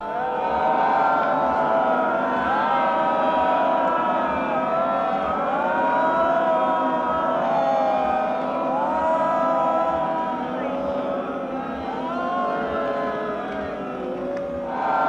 Wailing | Sneak On The Lot